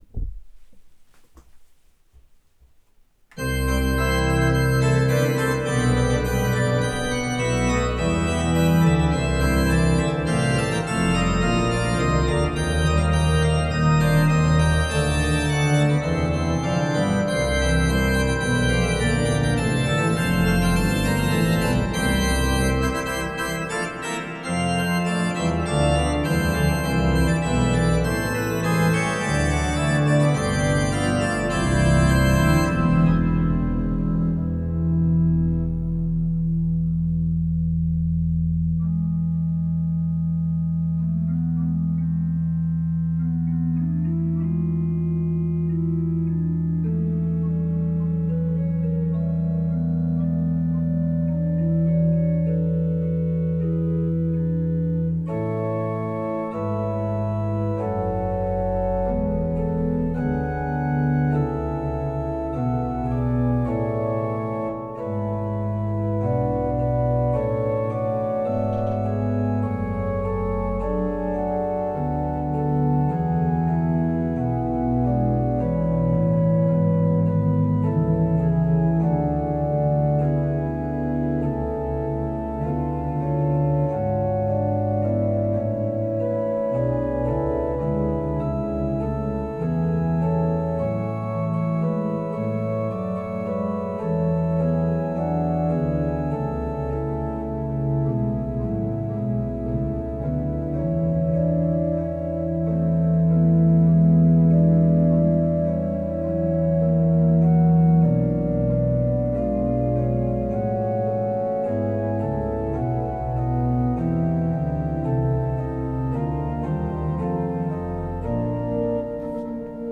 Studierende der Fachbereiche Kirchenmusik und Komposition der Musikhochschulen Frankfurt und Mainz haben kurze Orgelstücke komponiert, die am 12. September 2021 in vielen Kirchen in Hessen und Rheinland-Pfalz uraufgeführt wurden.
Die Kompositionen sind freie, nicht choralgebundene Stücke, mit einer Spieldauer von 3-4 Minuten, auf einer einmanualigen Orgel mit Pedal darstellbar und vom Schwierigkeitsgrad her auch für nebenberufliche Organist*innen mit C- oder D-Prüfung spielbar.